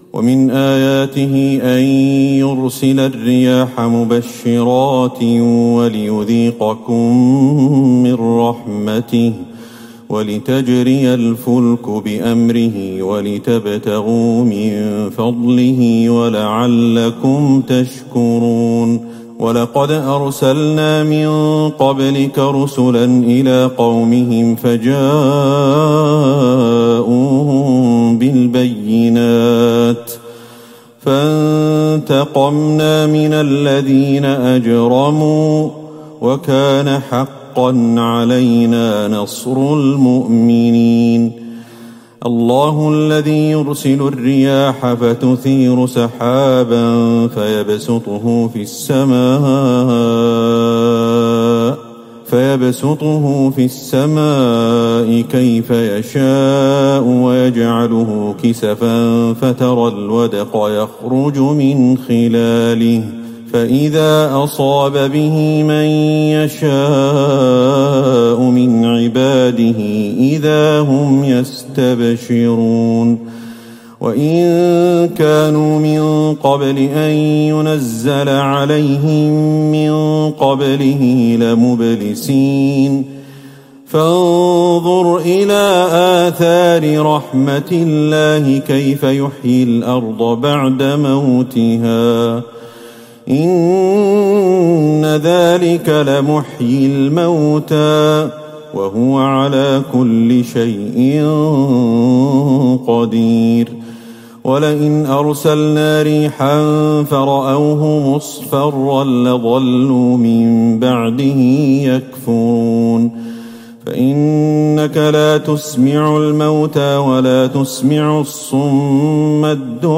تراويح ٢٤ رمضان ١٤٤١هـ من سورة الروم { ٤٦-٦٠ } ولقمان كاملة والسجدة { ١-٩ } > تراويح الحرم النبوي عام 1441 🕌 > التراويح - تلاوات الحرمين